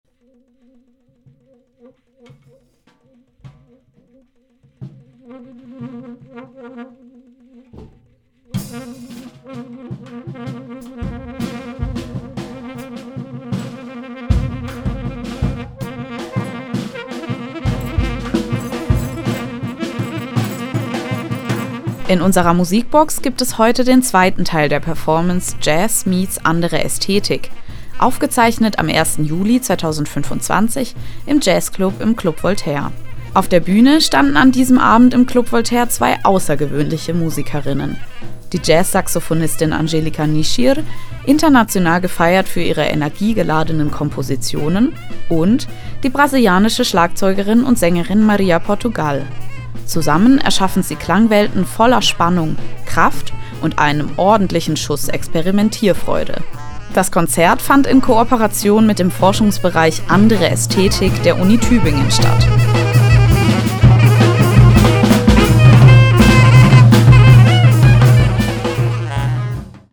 aufgezeichnet am 1. Juli 2025 im Jazzclub im Club Voltaire.
Jazz-Saxophonistin
Schlagzeugerin und Sängerin